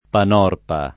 [ pan 0 rpa ]